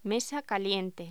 Locución: Mesa caliente
voz